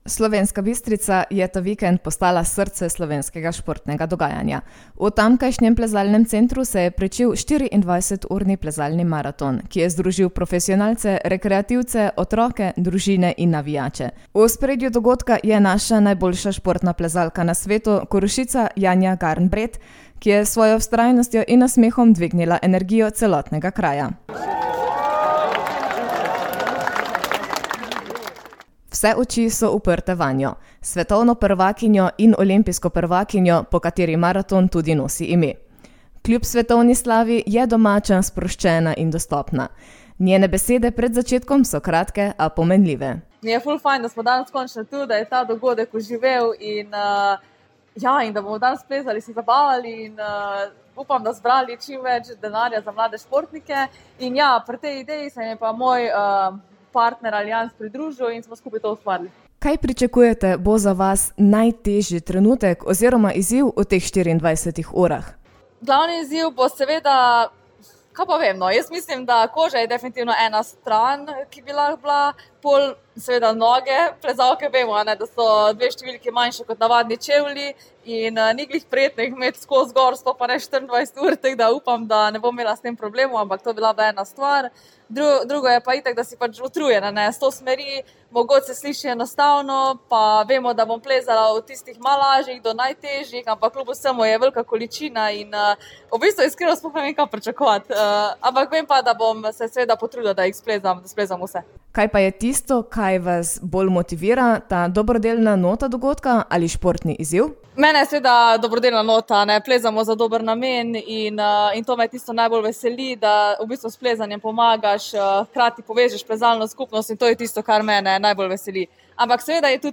Dobrodelni plezalni maraton v Slovenski Bistrici
Janja Garnbret - reportaza.mp3